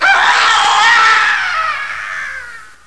screamM.wav